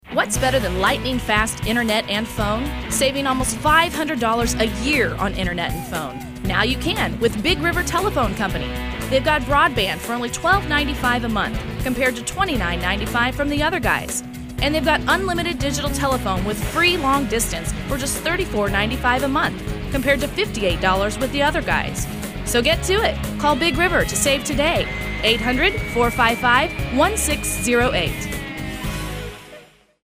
Big River Telephone Company - :30 radio